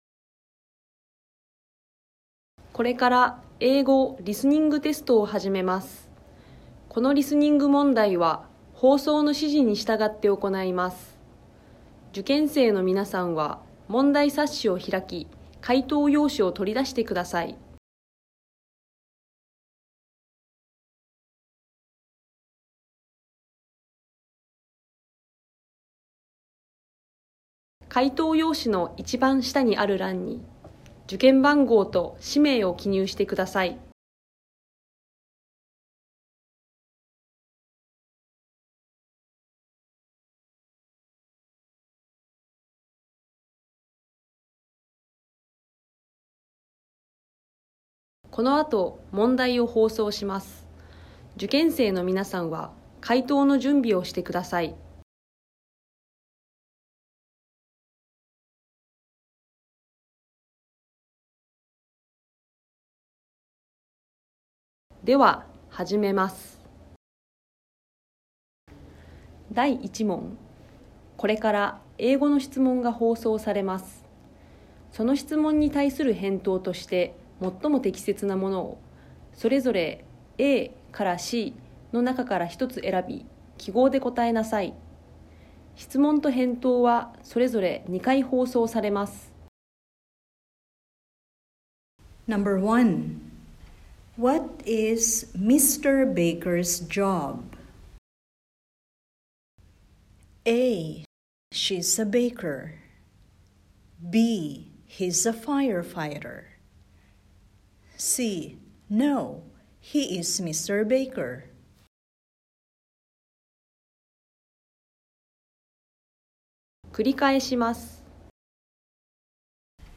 英語リスニング